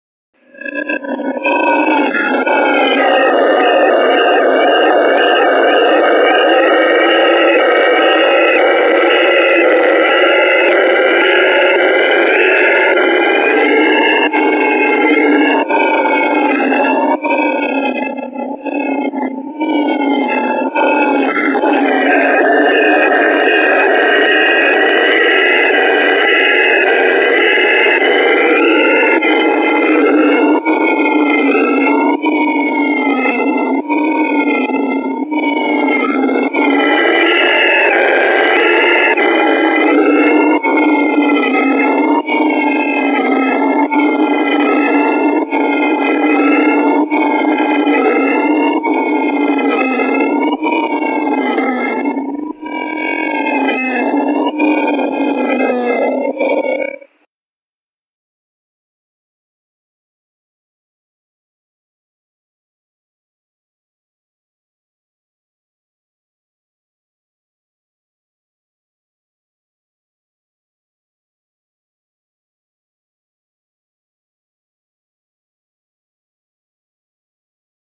Sounds—bird calling out to each other, cicadas announcing their presence, monkeys, especially the terrifying sound of howlers click here for a brief sound:
howler-monkey-sound-effect-angry-screaming-talking-leuctz2q5.mp3